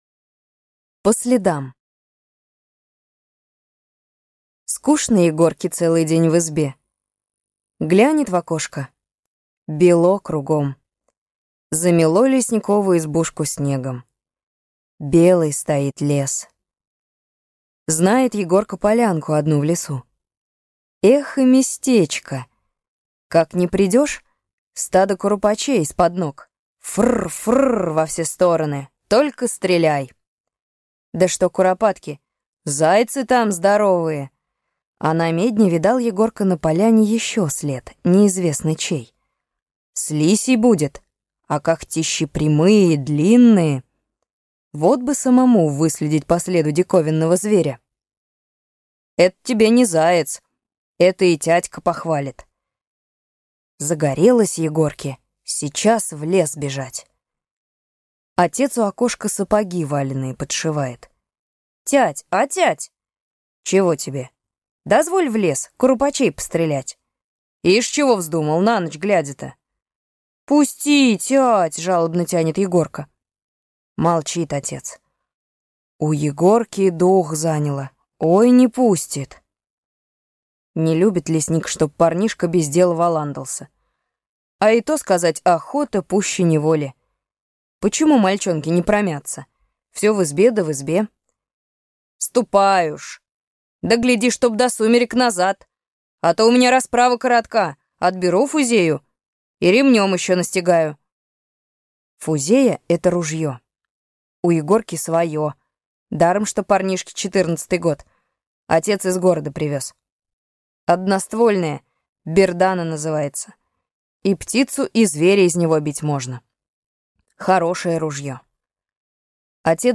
По следам - аудио рассказ Бианки - слушать онлайн